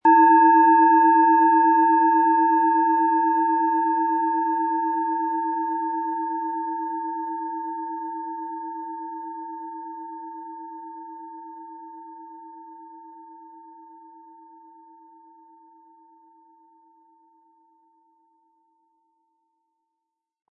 Alphawelle
Der Klöppel lässt die Klangschale voll und harmonisch tönen.
MaterialBronze